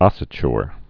(ŏsə-chr, -chər)